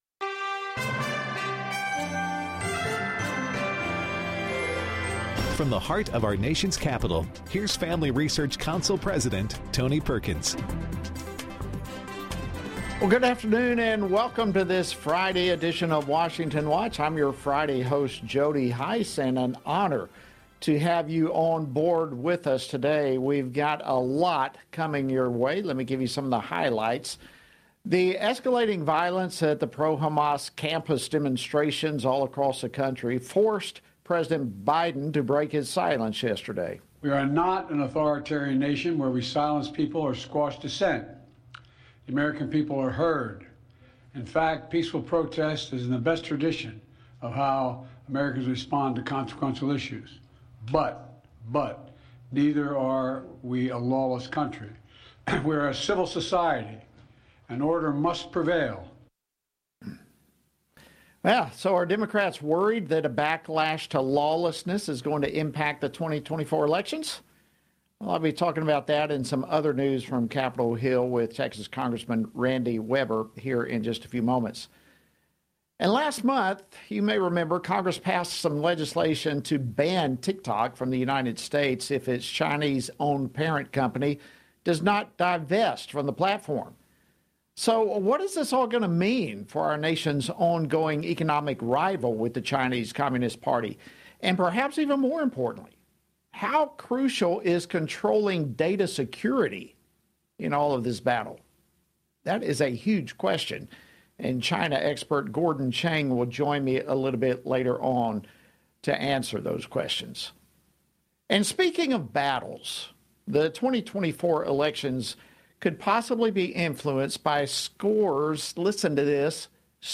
On today’s program, hosted by Jody Hice: Randy Weber, U.S. Representative for the 14th District of Texas, reacts to the continuing anti-Israel demonstrations on college campuses and provides analysis for how this could affect upcoming elections. Gordon Chang, Distinguished Senior Fellow at the Gatestone Institute, explains how data security has emerged as the next frontier in the United States’ economic rivalry with the Chinese Communist Party.